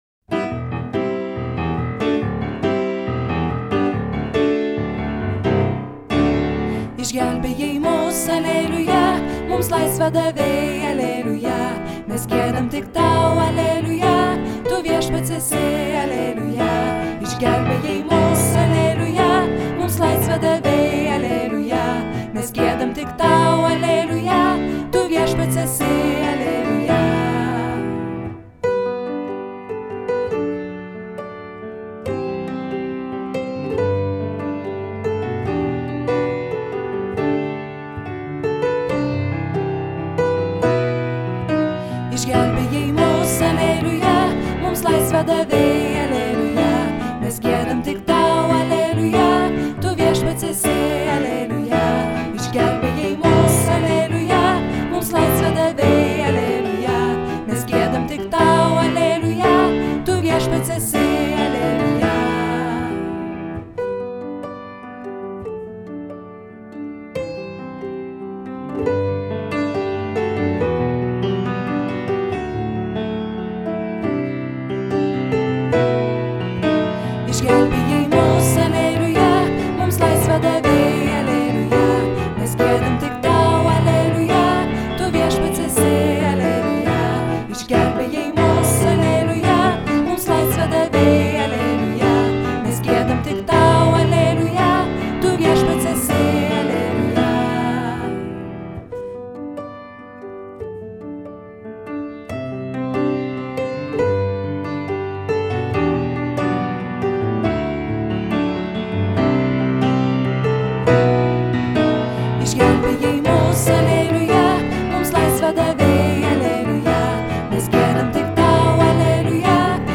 Altas: